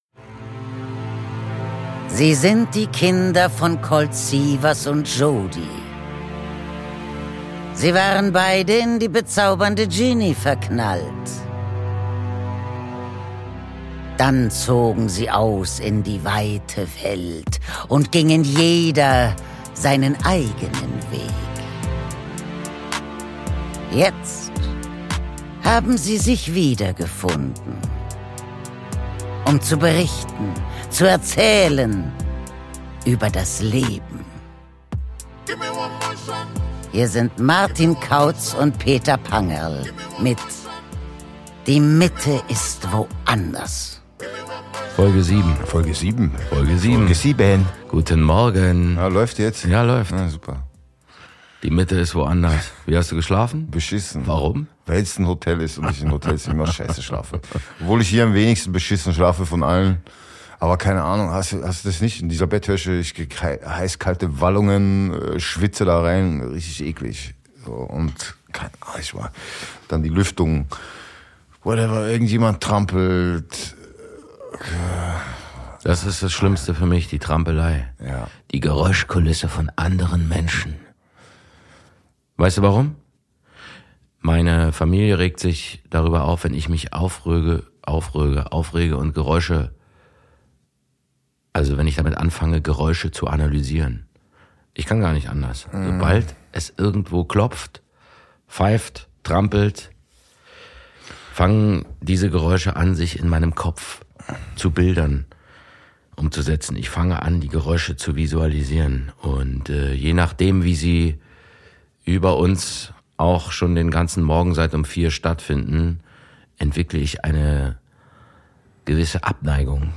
Aus dem 25h Hotel im Wiener Museumsquartier